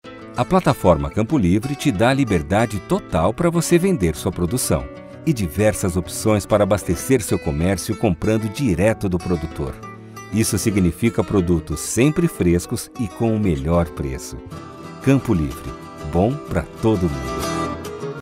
Portugués (Brasil)
Comercial, Natural, Seguro, Amable, Empresarial
Comercial